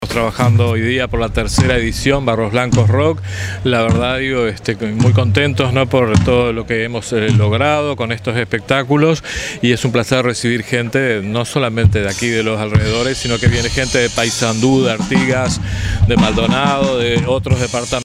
alcalde_de_barros_blancos_julian_rocha.mp3